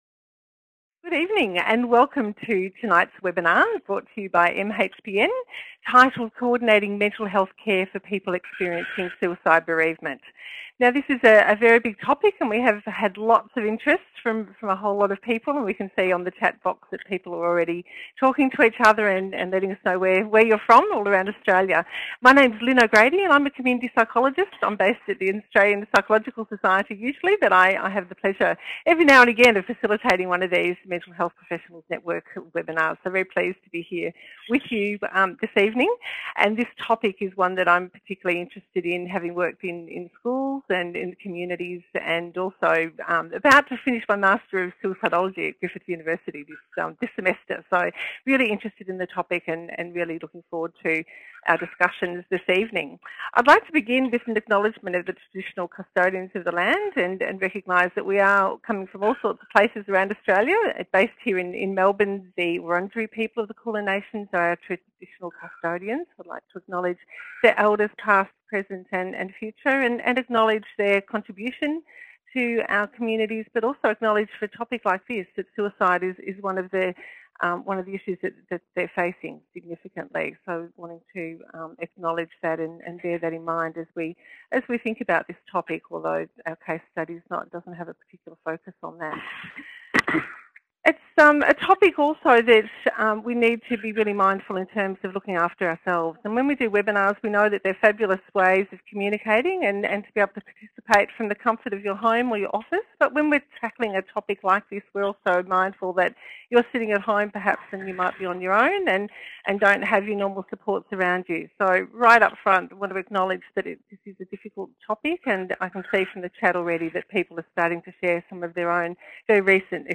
Join our interdisciplinary panel of experts to explore working collaboratively to support people experiencing suicide bereavement.